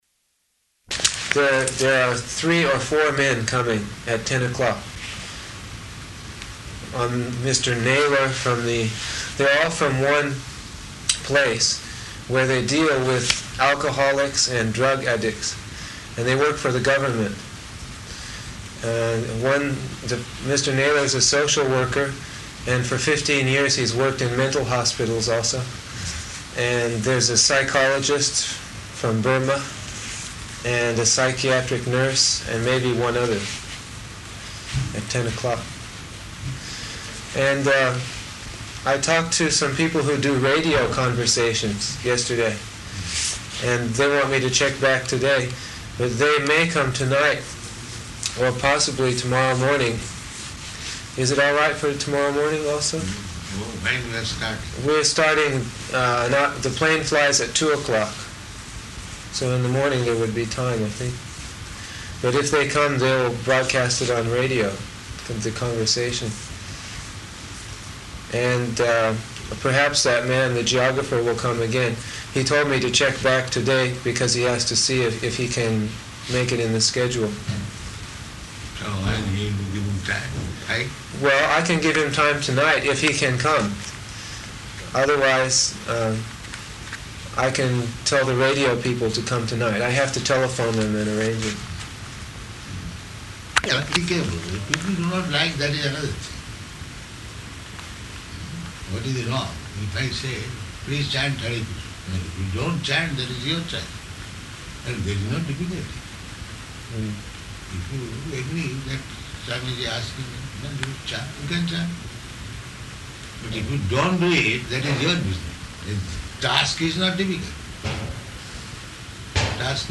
Room Conversation
Location: Perth